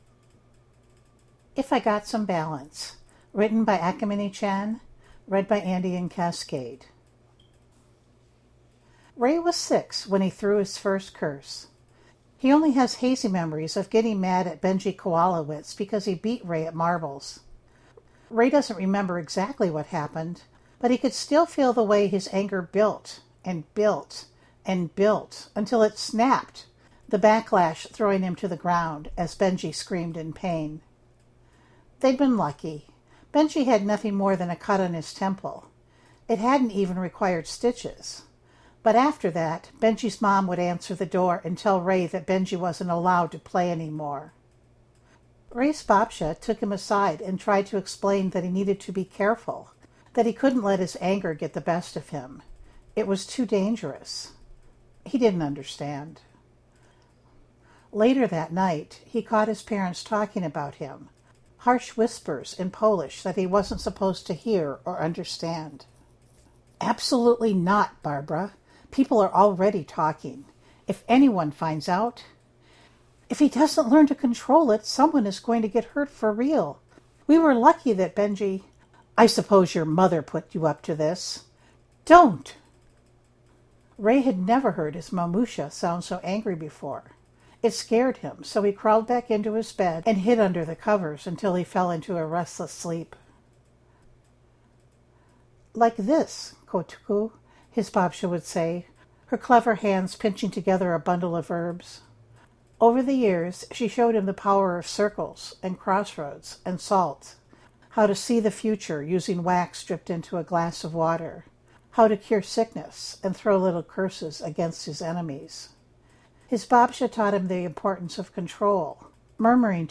[podfic] if I got some balance